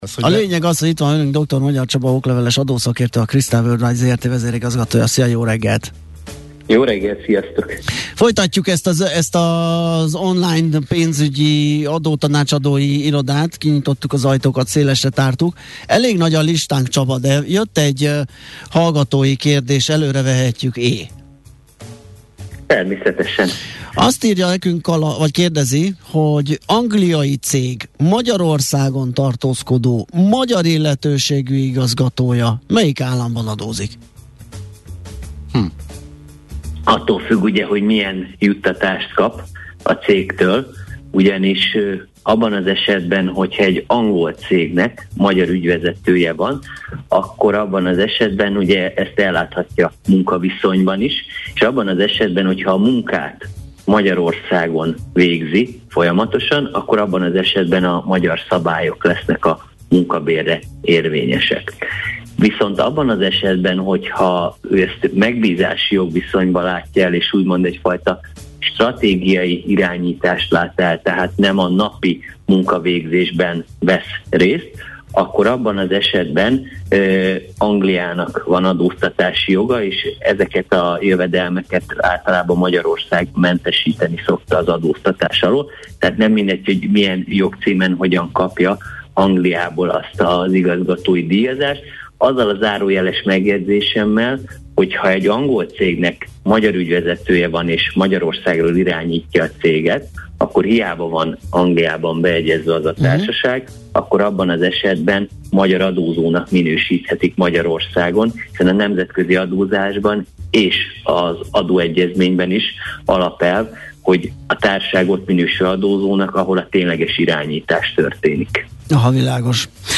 Műfaj: Blues.